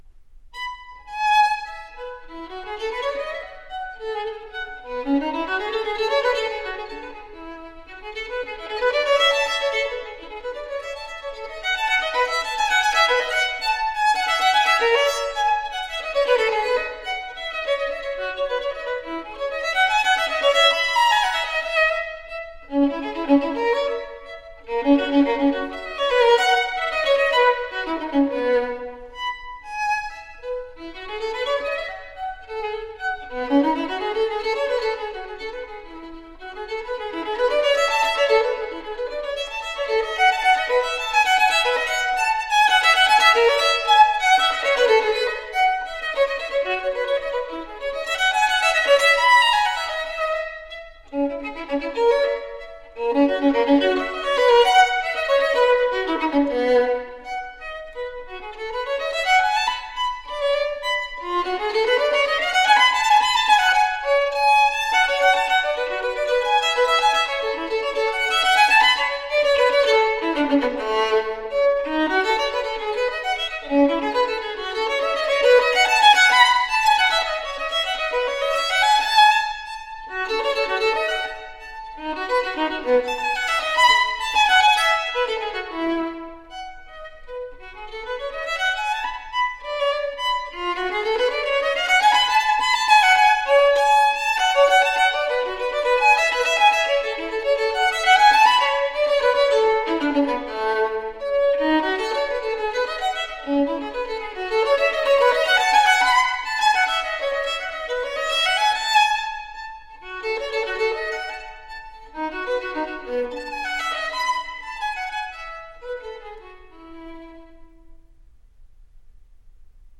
so the resulting music sounds incredibly fresh and alive.
Classical, Baroque, Instrumental